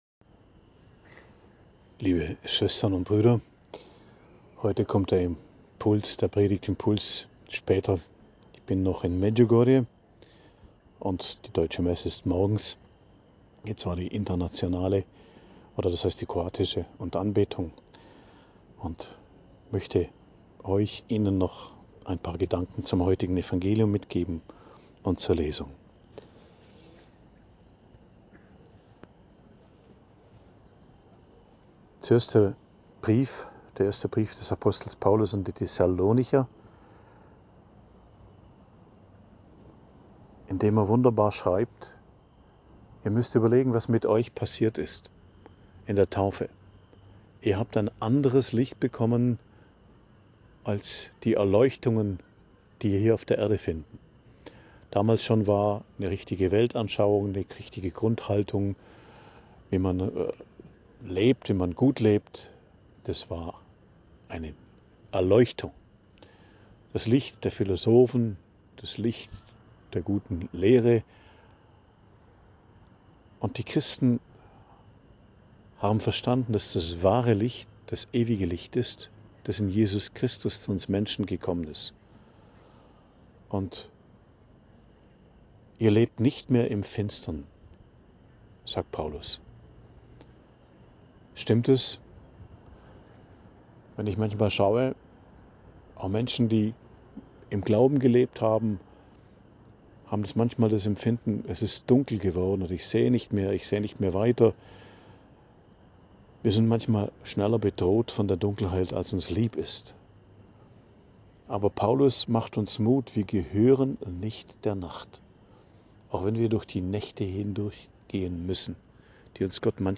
Predigt am Dienstag der 22. Woche i.J., 2.09.2025